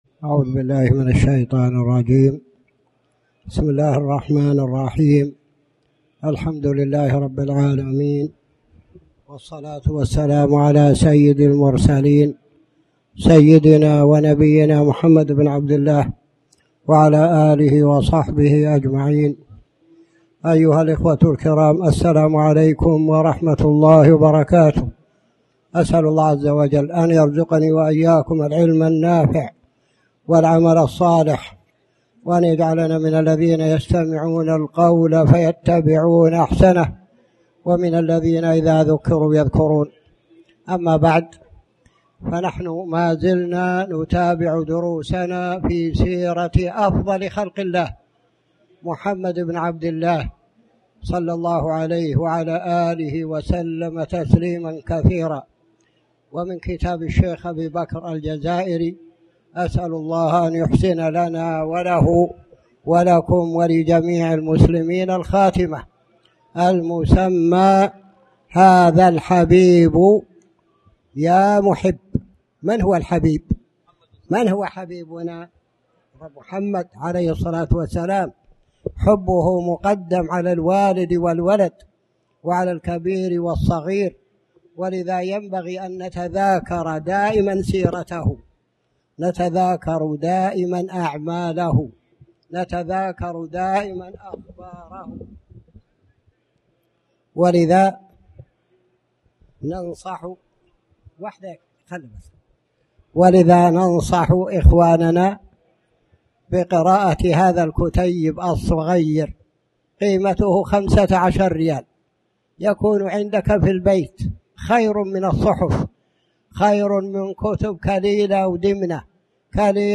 تاريخ النشر ٢٠ محرم ١٤٣٩ هـ المكان: المسجد الحرام الشيخ